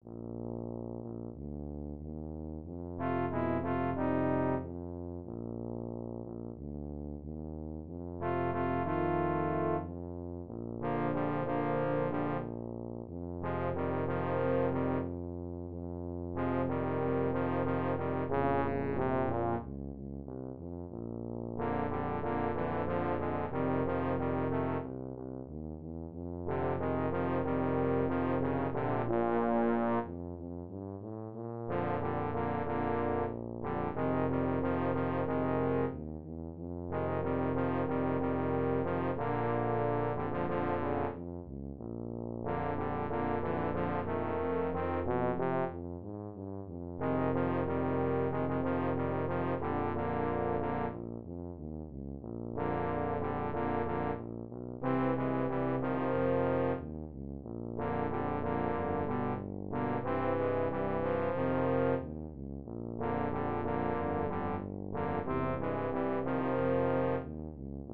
for tuba and euphonium